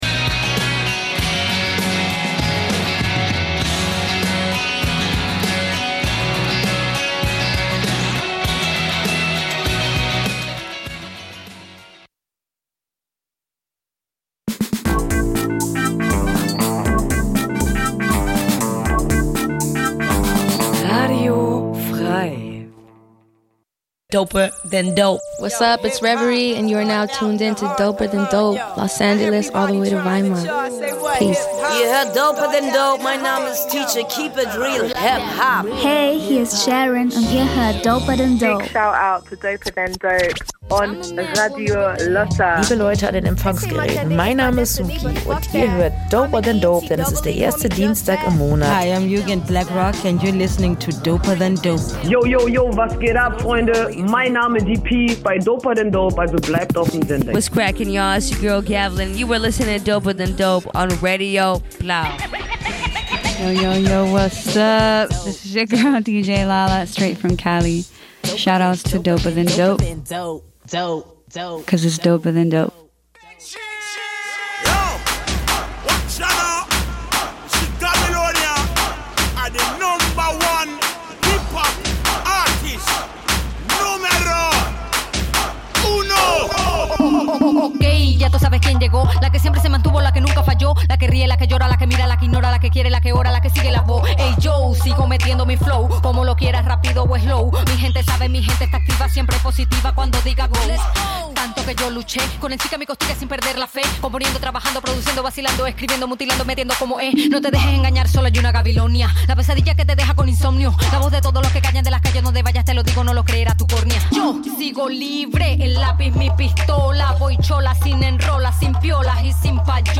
Bei doper than dope wird HipHop-Kultur gefeiert!